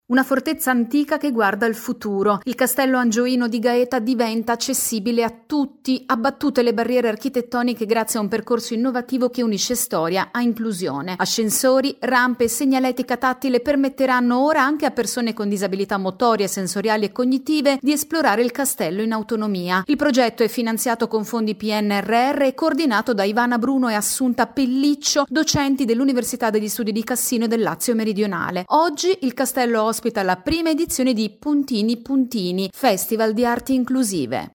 Oltre i confini – Il Castello di Gaeta abbatte le barriere e diventa inclusivo. Il servizio